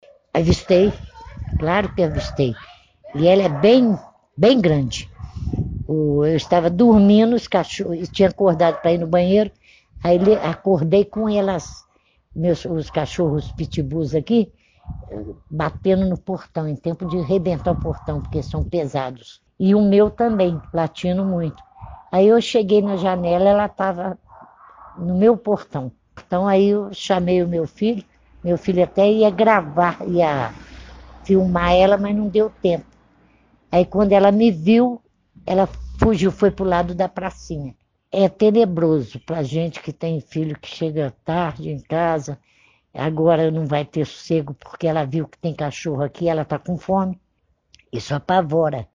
moradora